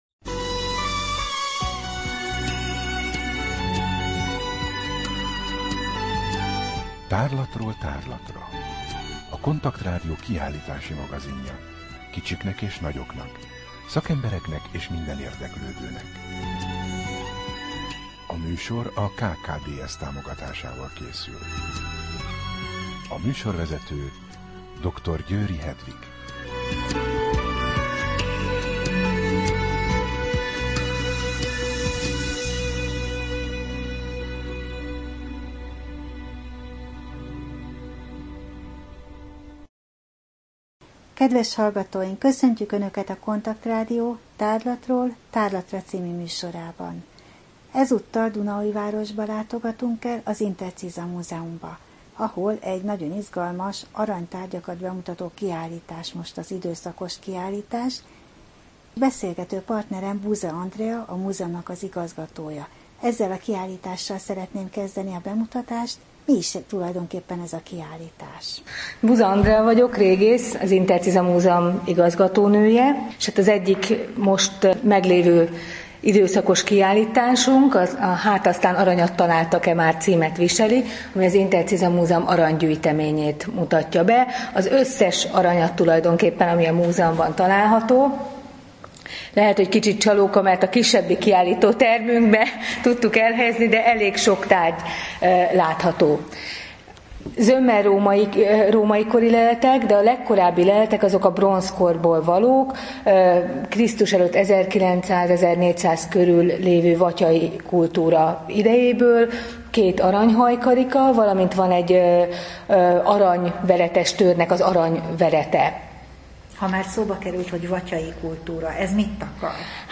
Rádió: Tárlatról tárlatra Adás dátuma: 2013, February 4 Tárlatról tárlatra / KONTAKT Rádió (87,6 MHz) 2013 február 4.